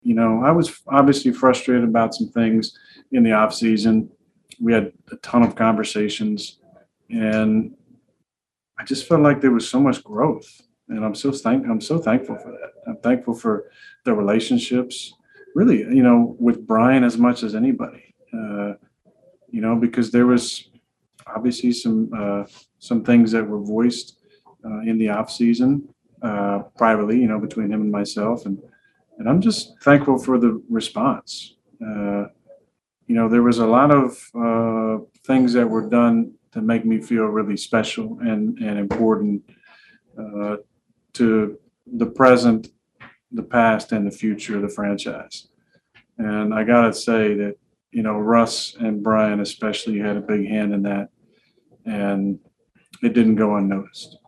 Following the ceremony, he met with members of the media and talked some more about the honor and what the future holds for him.